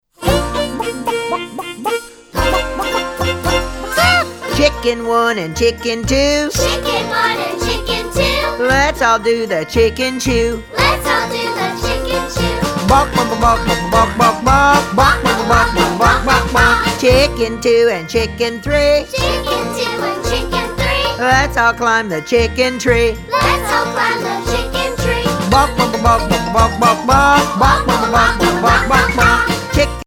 hand clappin', foot stompin' fun for everyone!